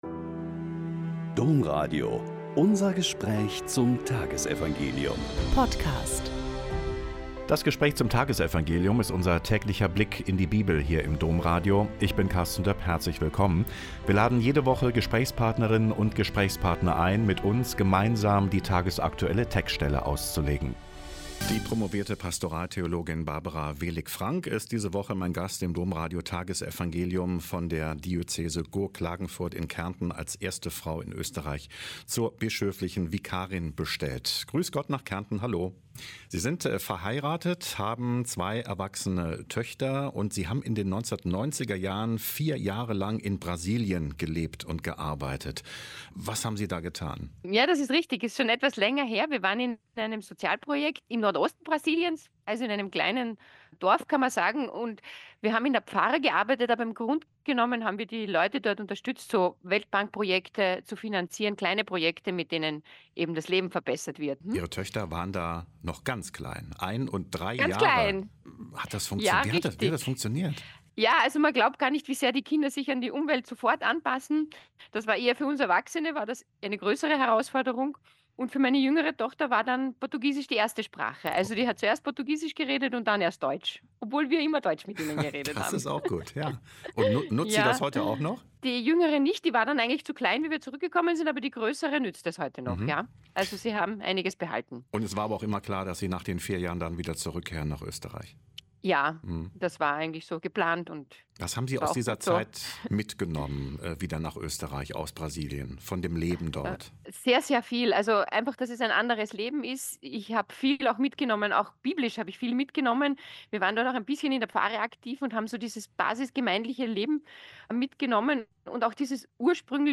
Lk 19,11-28 - Gespräch